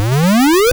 その他の効果音 試聴ダウンロード ｜ seadenden 8bit freeBGM